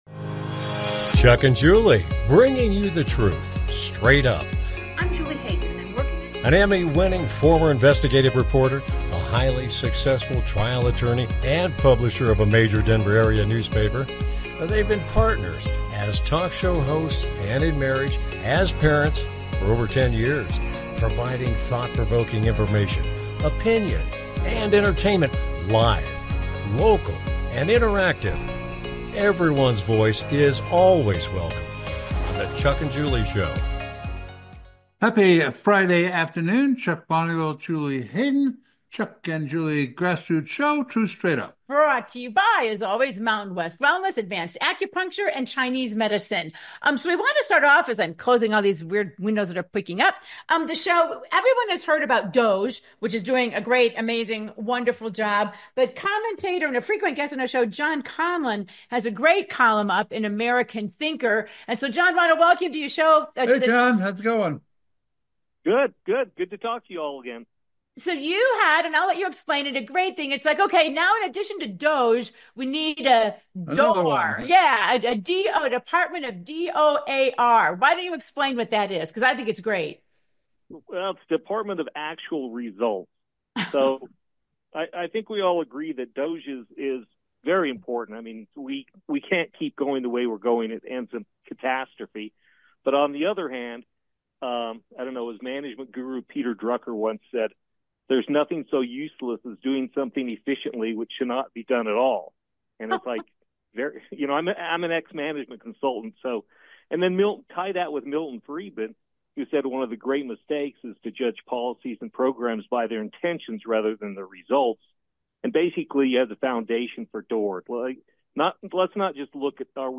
With guest, Commentator